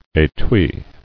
[é·tui]